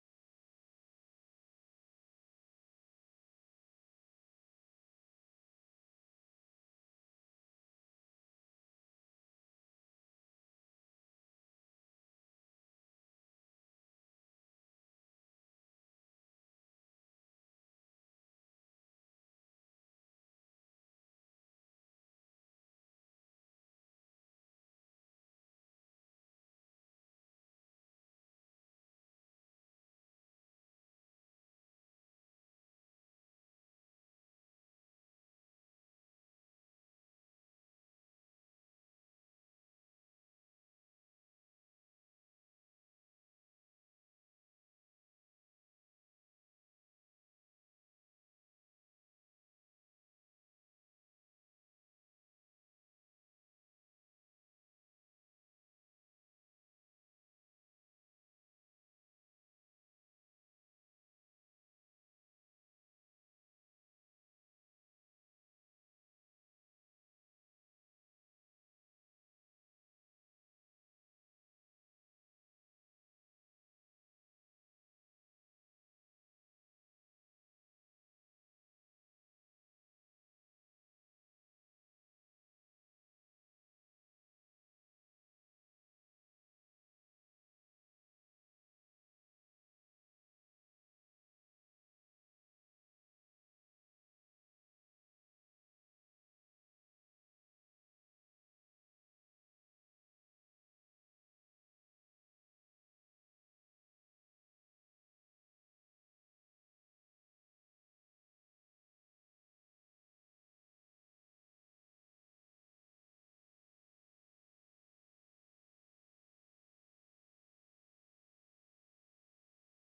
October 20th 2024 Praise and Worship